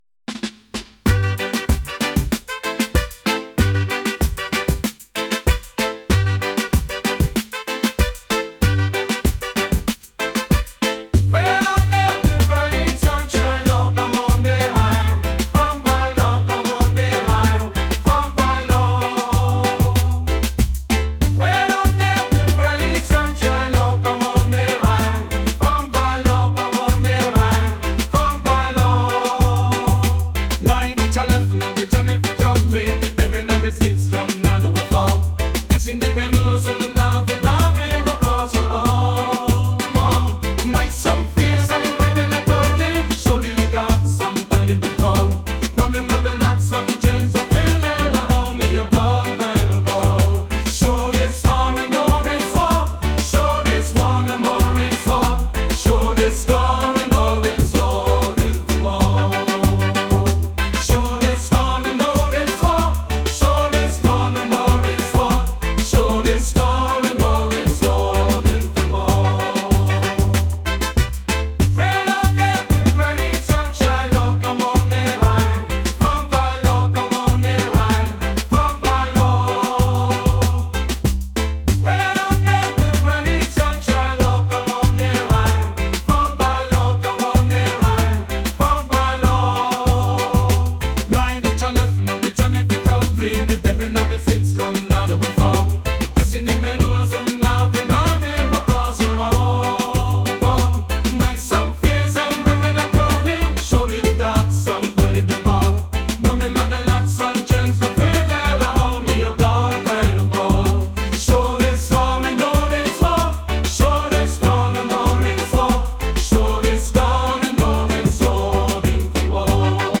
reggae | pop | electronic